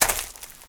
STEPS Leaves, Walk 04.wav